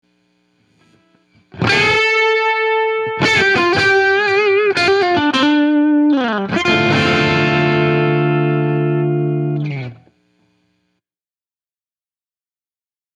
Теперь давайте попробуем применить несколько контрастных тонов, используя пресеты из множества различных плагинов.
Пресет «Настоящий день» из встроенного в Logic Pro Amp Designer.